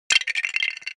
lego_breaking.mp3